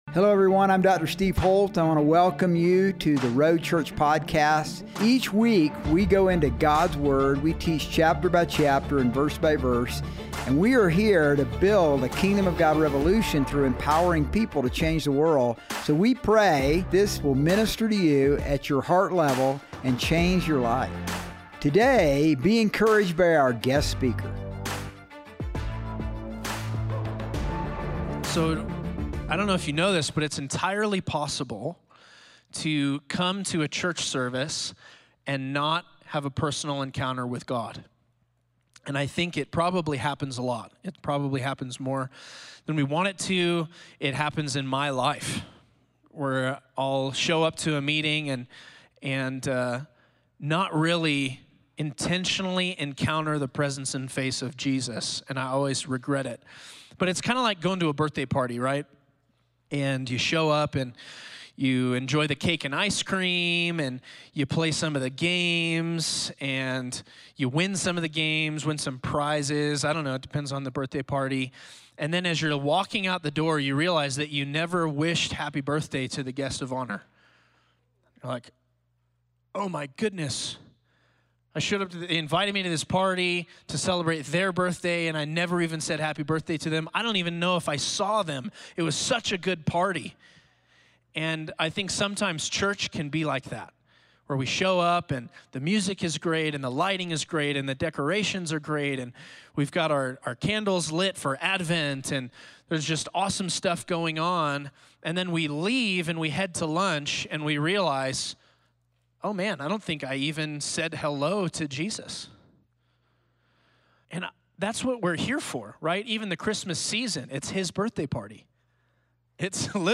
Sermons | The Road Church